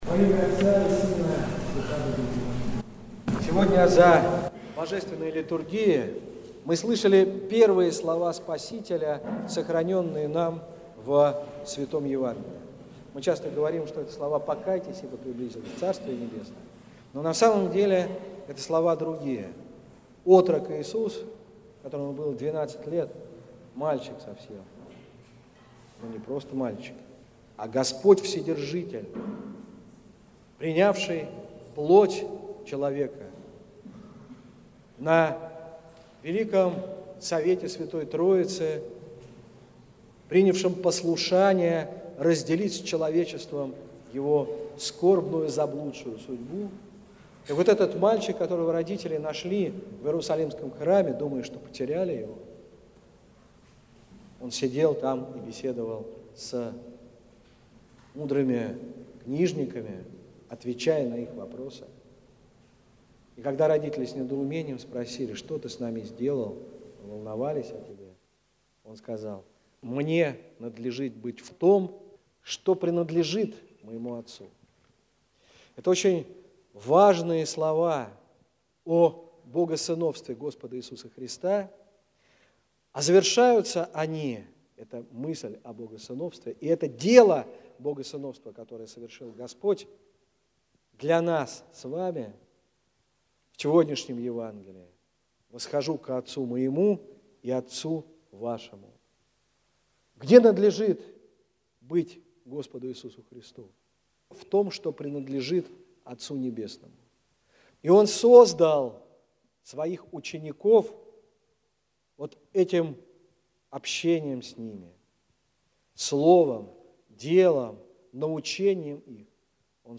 Слово на всенощном бдении накануне Недели 30-й по Пятидесятнице
Псковская митрополия, Псково-Печерский монастырь